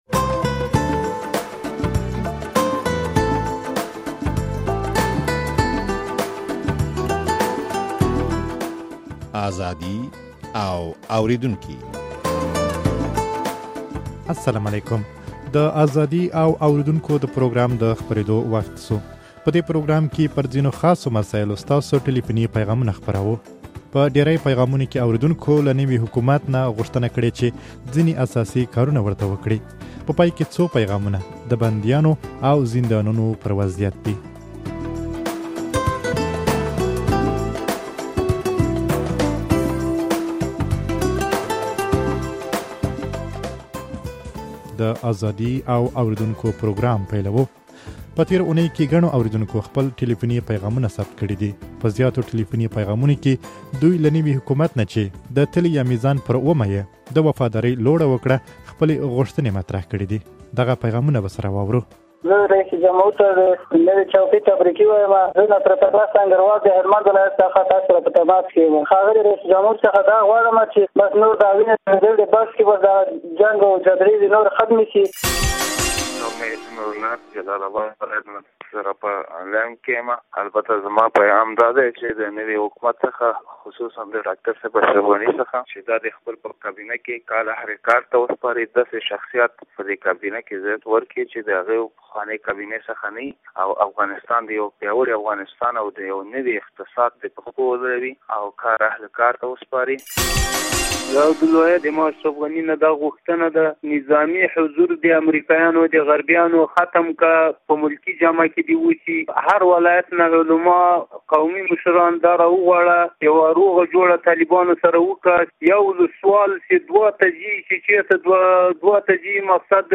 په دې پروګرام کې پر ځينو خاصو مسايلو ستاسو ټليفوني پيغامونه خپروو.